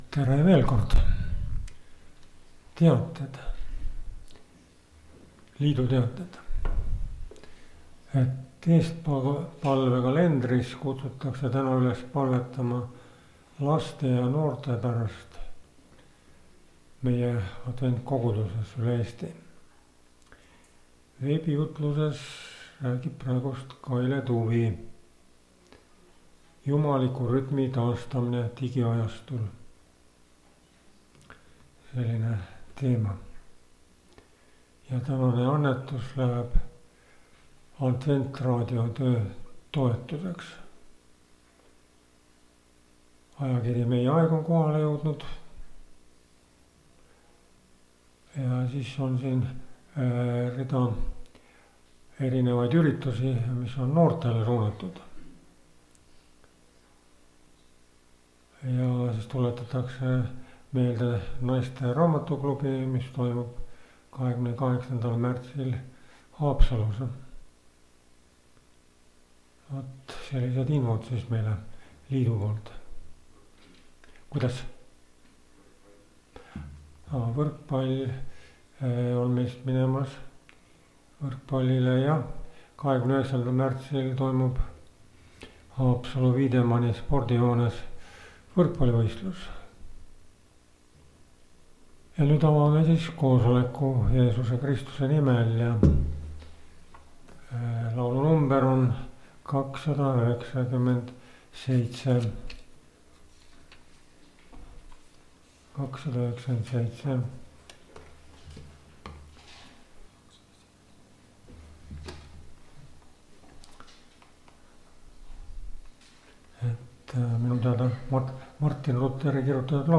kategooria Audio / Koosolekute helisalvestused